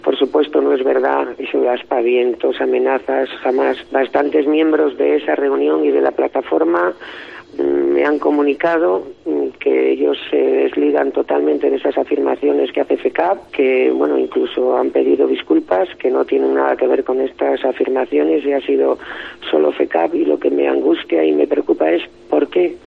Carmen Ruiz, concejala de barrios Ayuntamiento de Santander